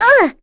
Rephy-Vox_Damage_01.wav